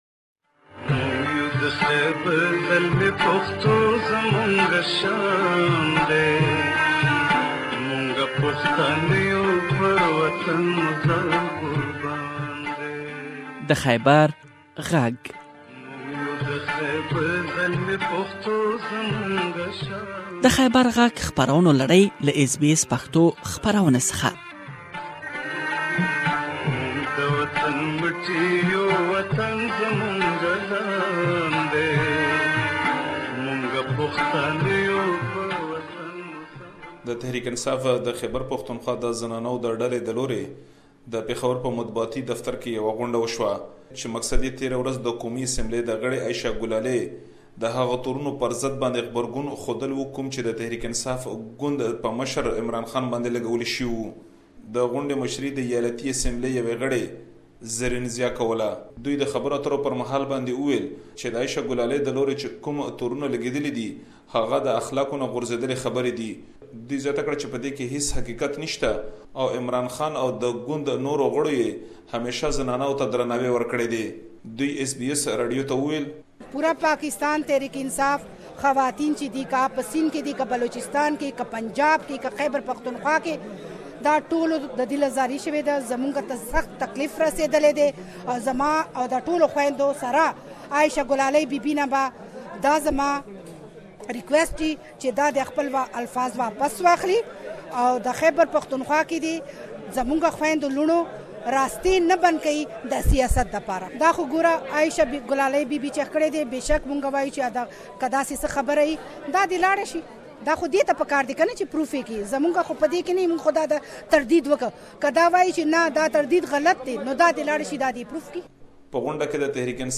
has spoken to some PTI female member, and you can listen to thier position in this report.